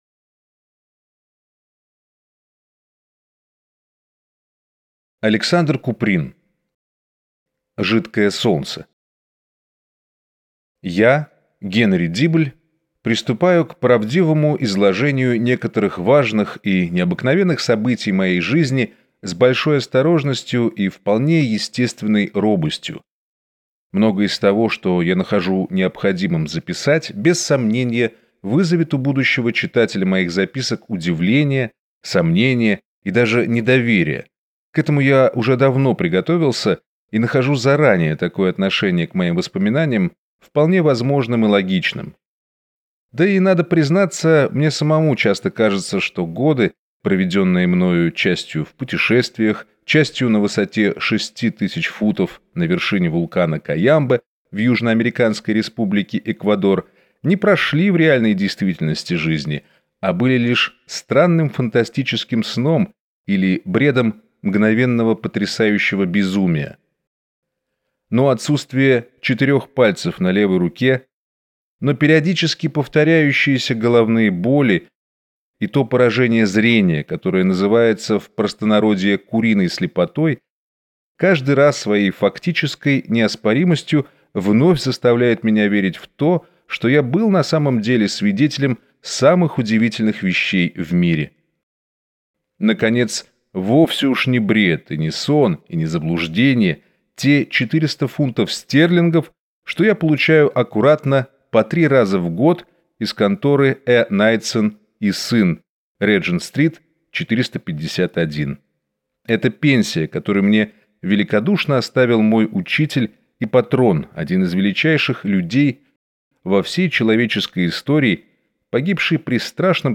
Аудиокнига Жидкое солнце | Библиотека аудиокниг